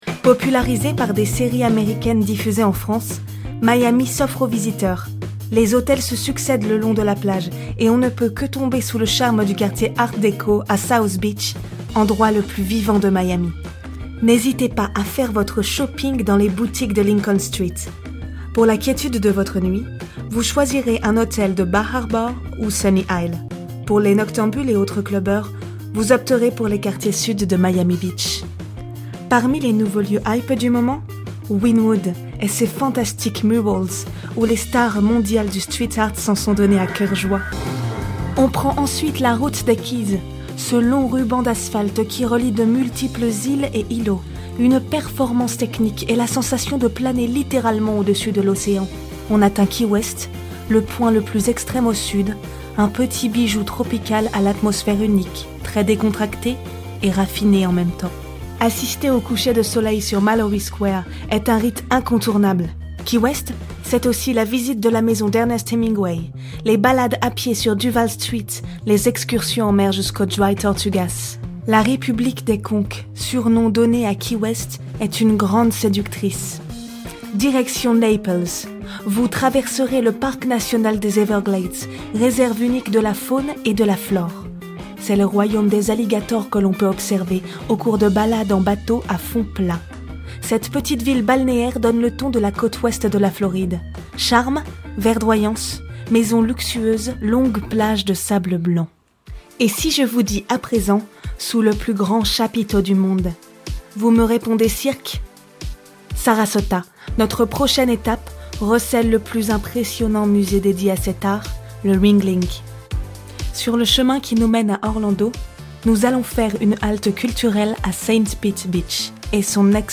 Voix off webpublicité pour Jetset voyage